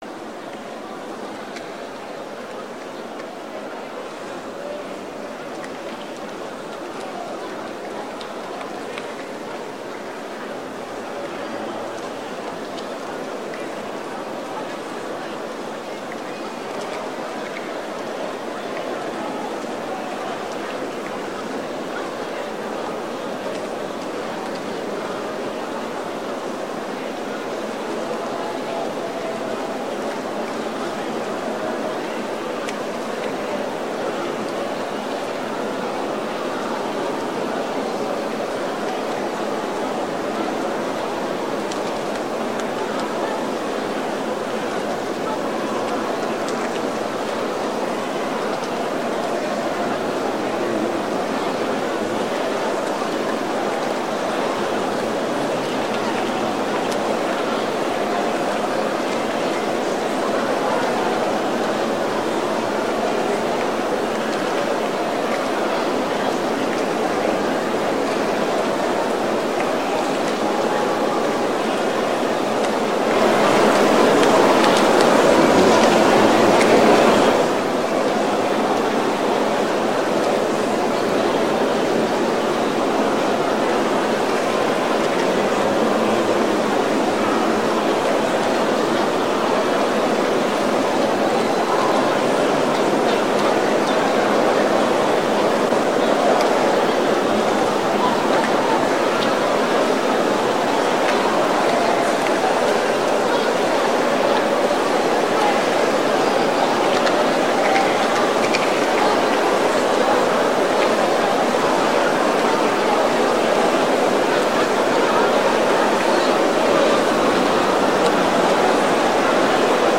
Sermons - Deeper Christian Life Ministry
2018 Revival Service